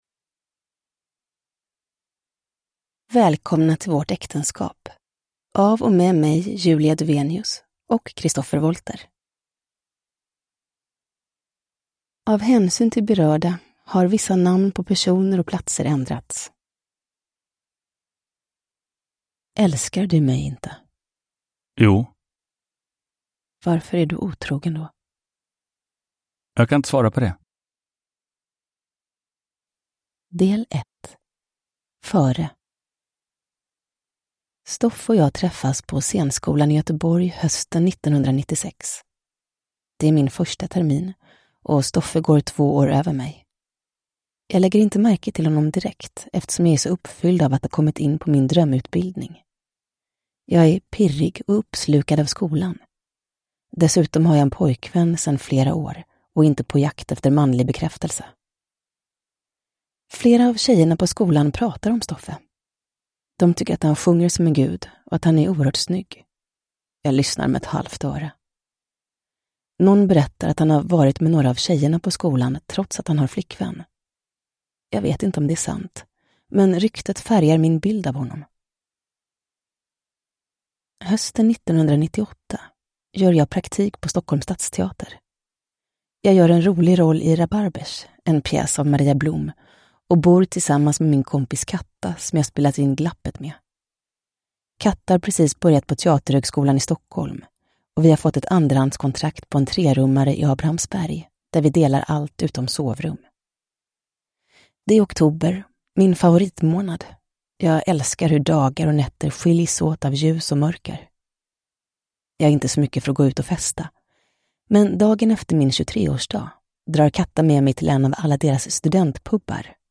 Välkomna till vårt äktenskap – Ljudbok
Uppläsare: Julia Dufvenius, Christopher Wollter